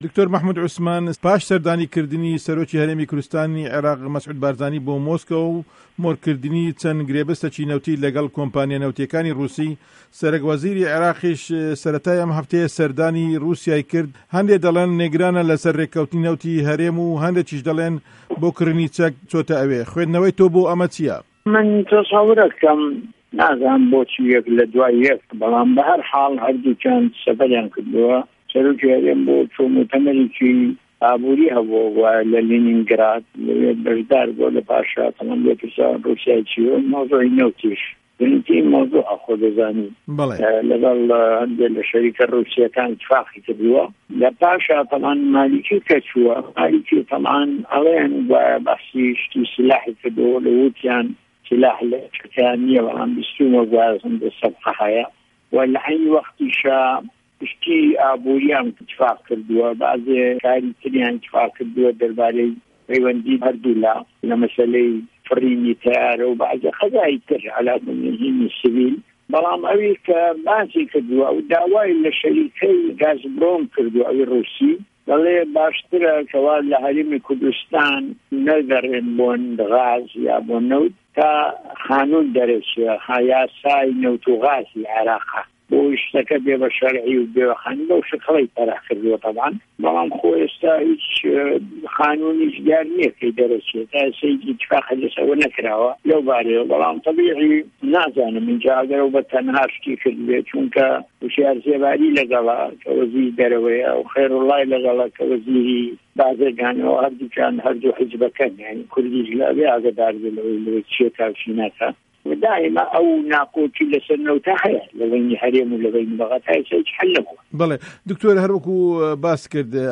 وتووێژی دکتۆر مه‌حمود عوسمان